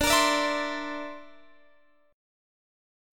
Listen to D7sus2#5 strummed